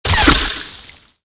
impactwatersmall04.wav